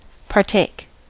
Ääntäminen
IPA : /pɑɹˈteɪk/ IPA : /pɑːˈteɪk/